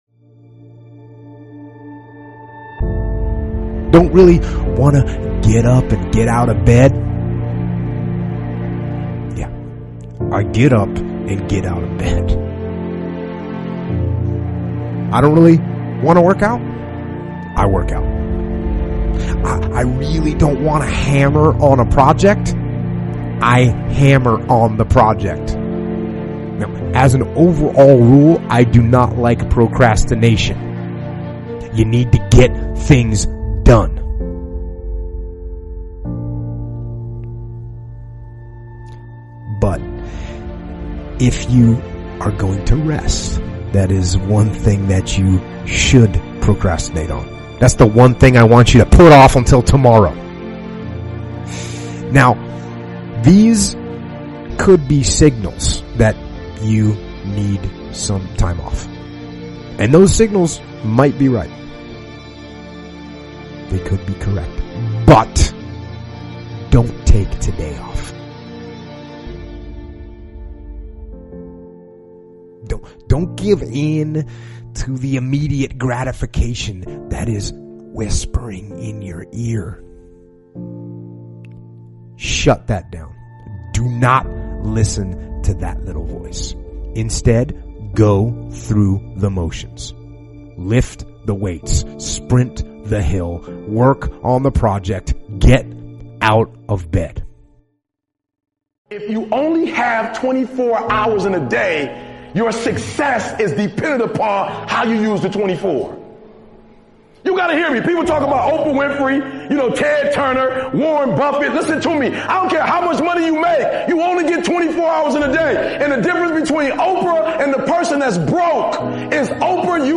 Speakers: Jocko Willink